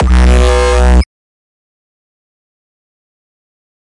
声音设计 " Huge Weird Breathing 声音设计
标签： 离奇 梦想 巨大的 迷幻的 如梦如幻 空气 元素 药品 怪物 呼吸 巨大的 巨大的
声道立体声